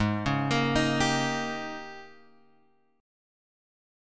G#M7sus2sus4 chord {4 4 x 3 4 3} chord